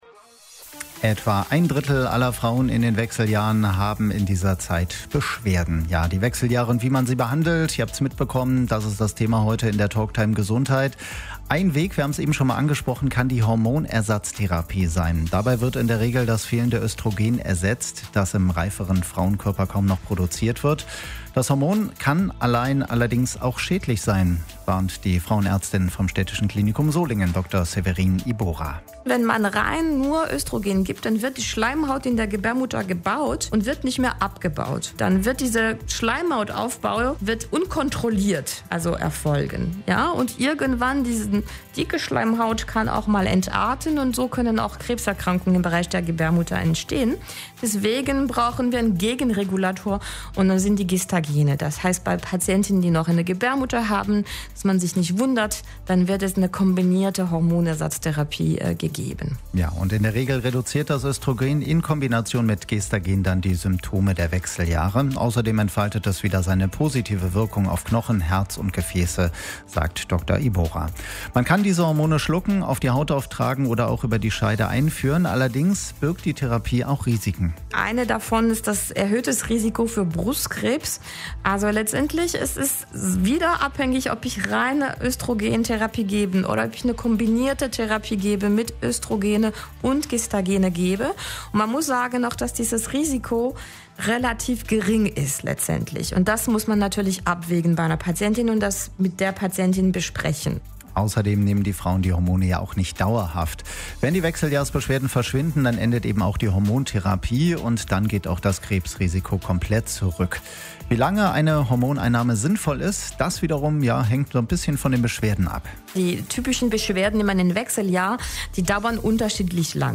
Die Talktime Gesundheit lief am Samstag, 21. Juni, von 12 bis 13 Uhr bei Radio RSG - wenn ihr etwas runterscrollt, könnt die Sendung hier noch einmal hören.